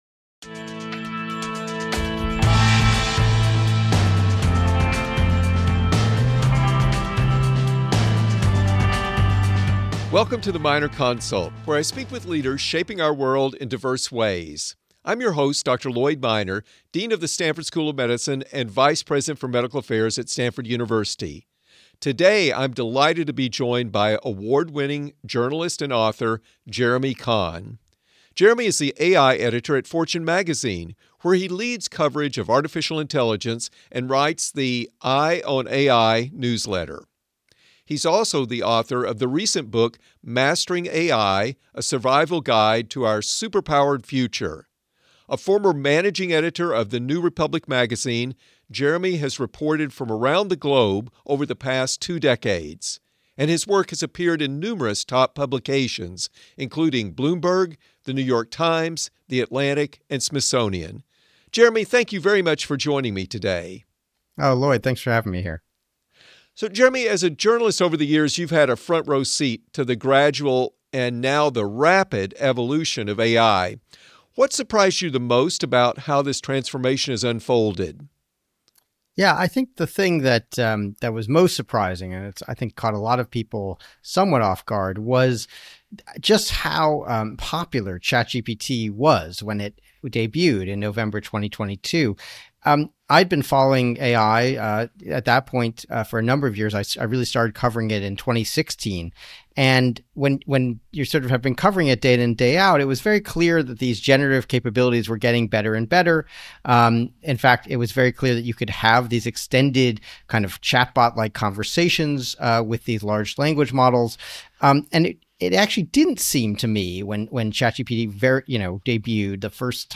for a conversation about the transformative potential and societal risks of artificial intelligence. They discuss opportunities for AI to accelerate medical breakthroughs and personalize education, as well as the threat of overreliance on AI to critical thinking, writing, and social skills. They also explore how AI is reshaping jobs and business models, the role of regulation in balancing innovation with safety, and the geopolitical stakes as global competition in AI intensifies.